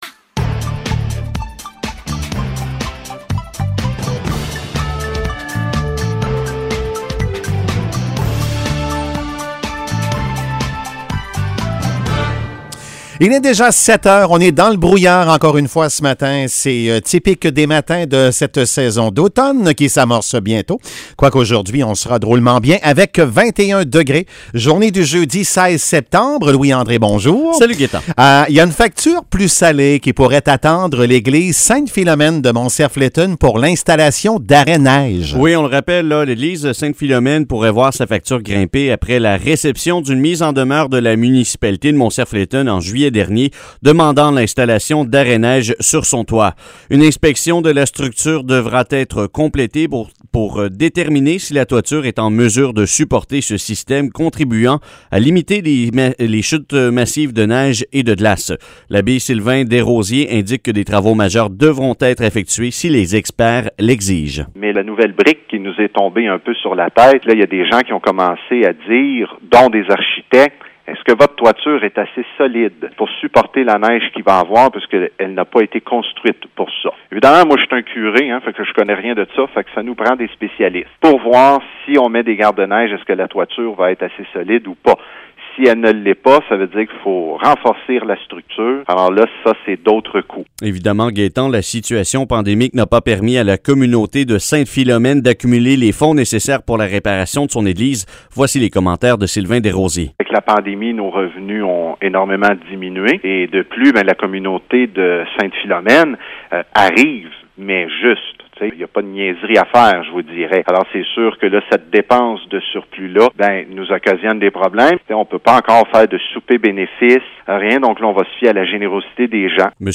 Nouvelles locales - 16 septembre 2021 - 7 h